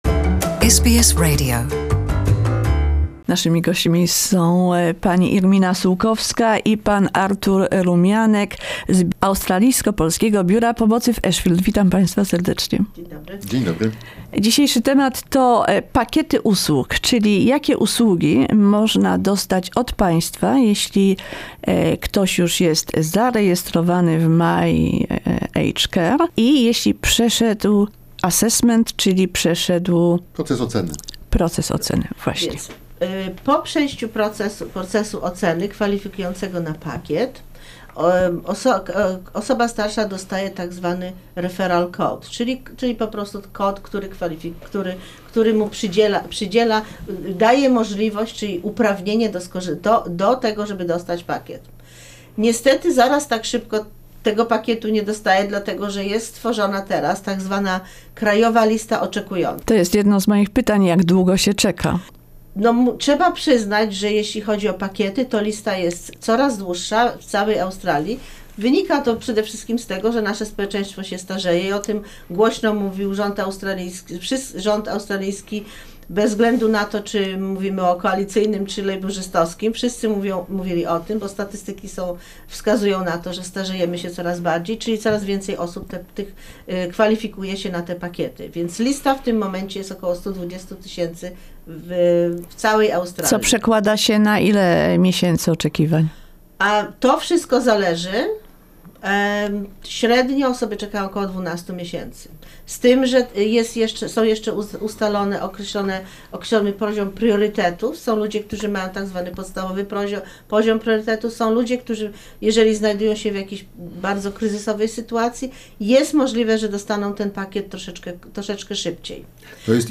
This is part 3 of the interview.